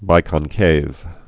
(bīkŏn-kāv, bī-kŏnkāv)